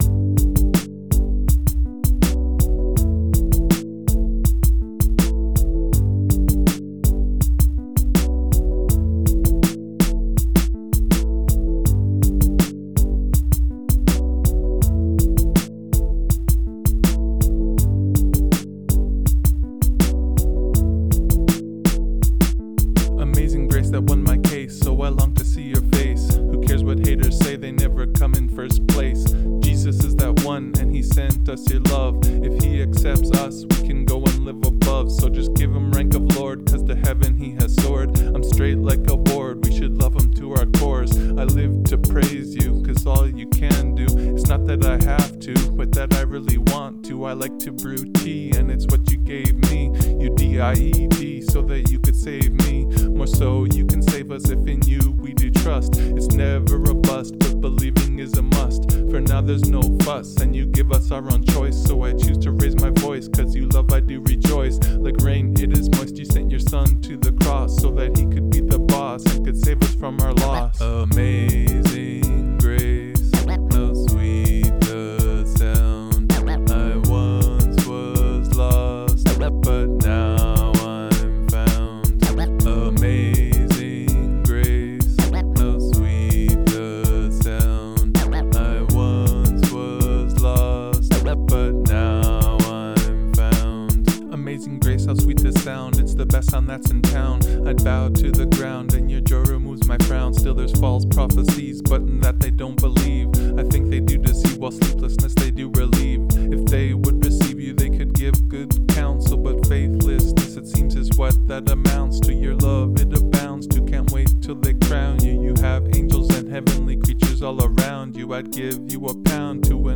Christian rappers